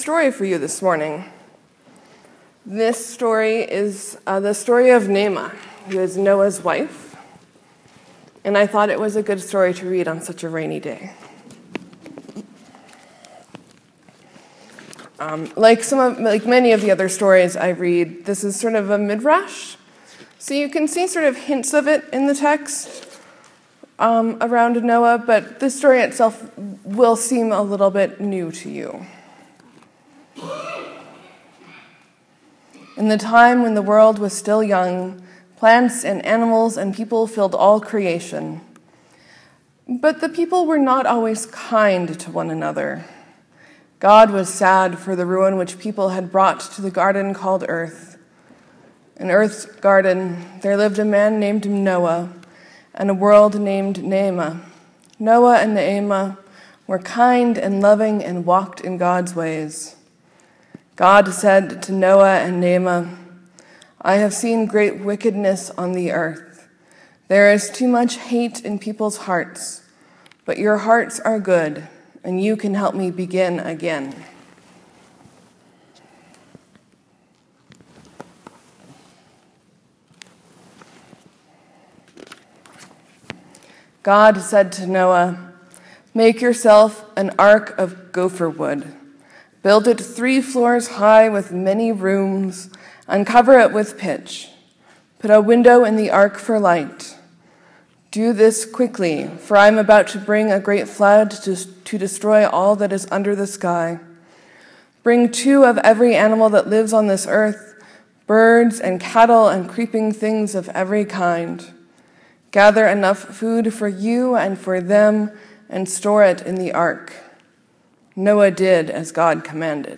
Sermon: Jesus does something really significant and difficult when confronted with rumours and gossip. He manages to set boundaries and leave space for change.